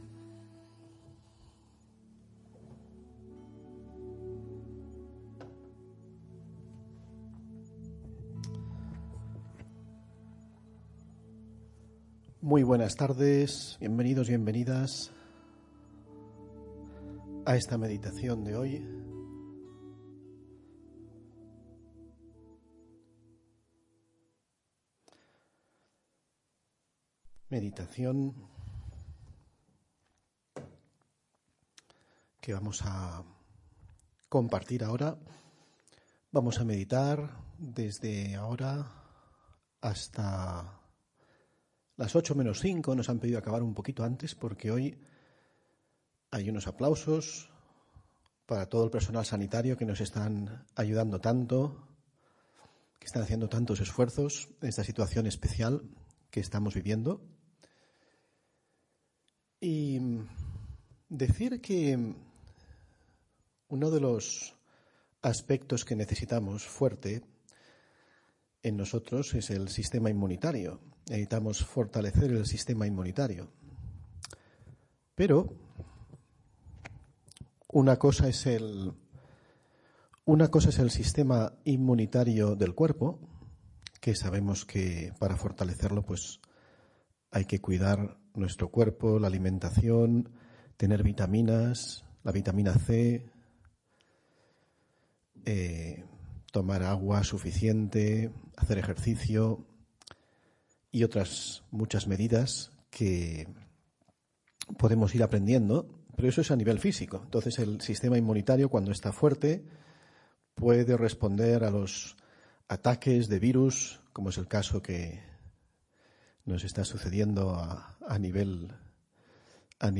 meditacion_ser_un_faro_luz.mp3